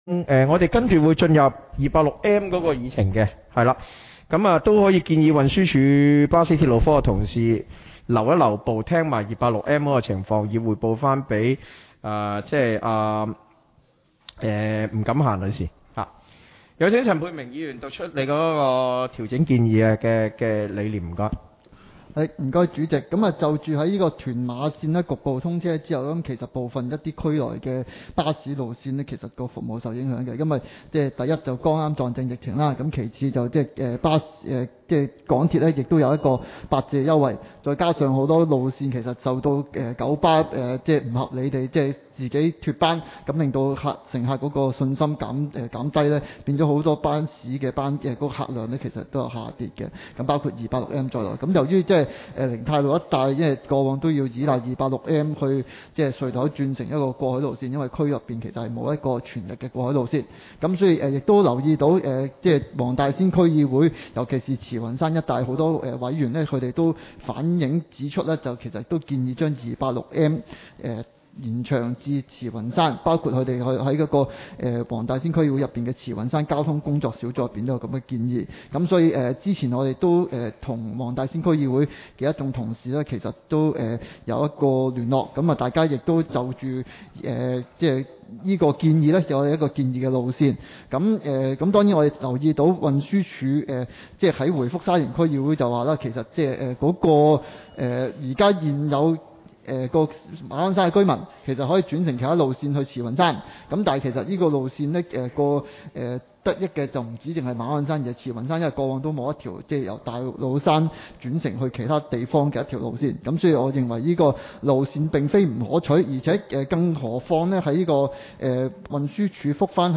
委员会会议的录音记录
地点: 沙田民政事务处 441 会议室